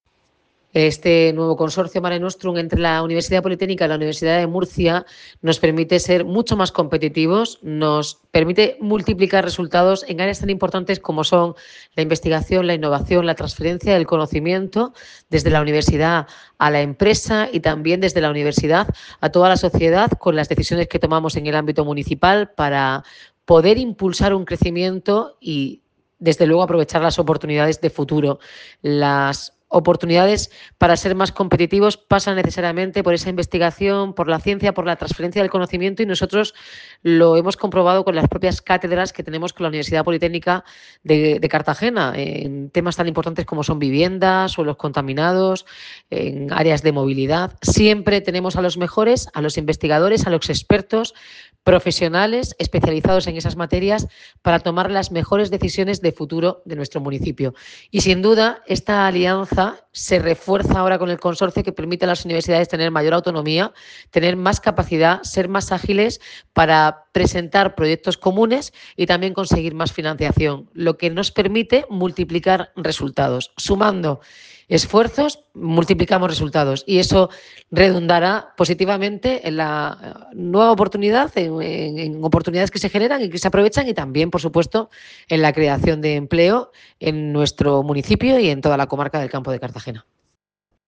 Enlace a Declaraciones de la alcaldesa Noelia Arroyo.